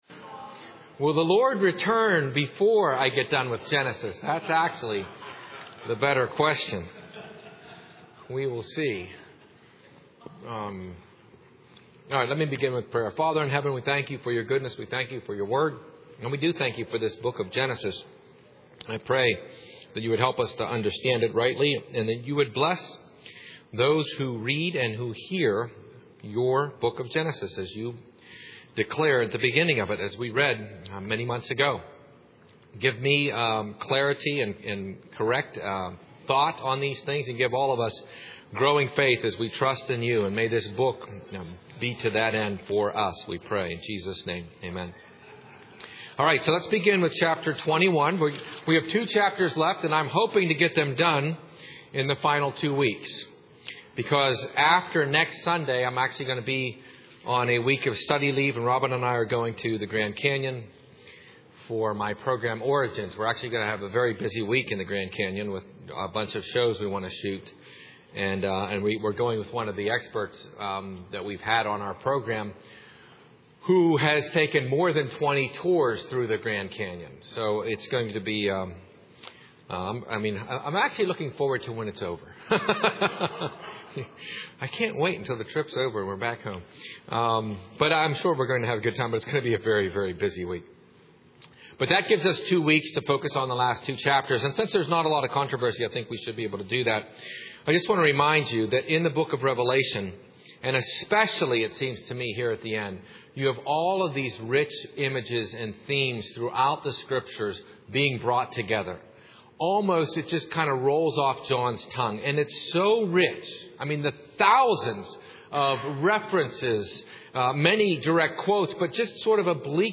Browse audio sermons and lectures by series.